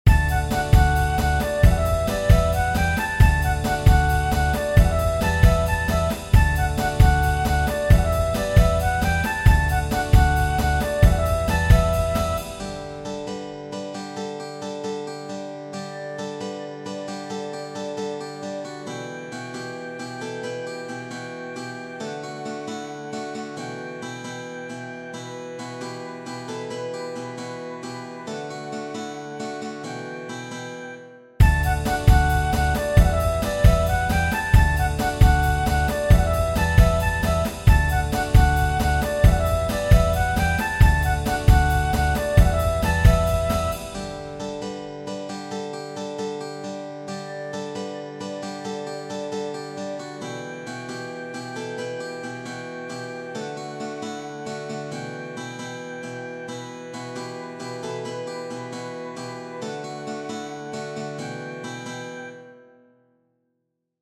Tradizionale Genere: Folk Testo di anonimo Mori Riso, Riso, /2 kade si odila, Riso, doma ne si bila.